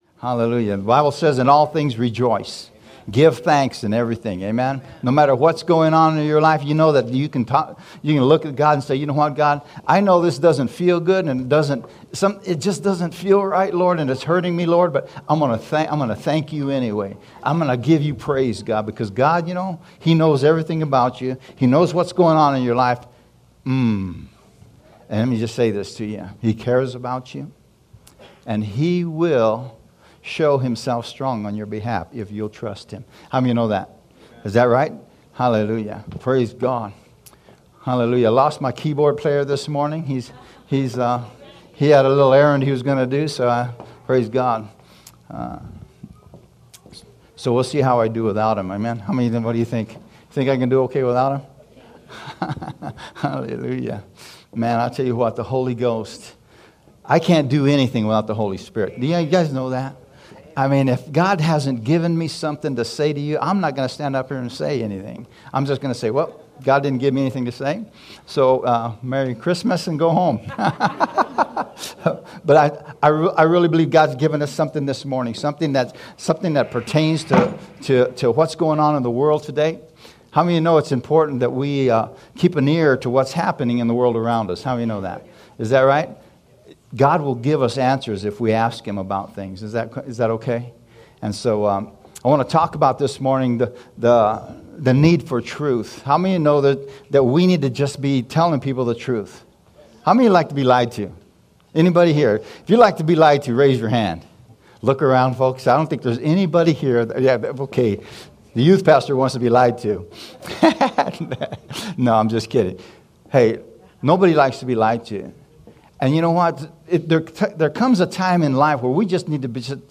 SHOW Sermon July 31st
Audio Sermon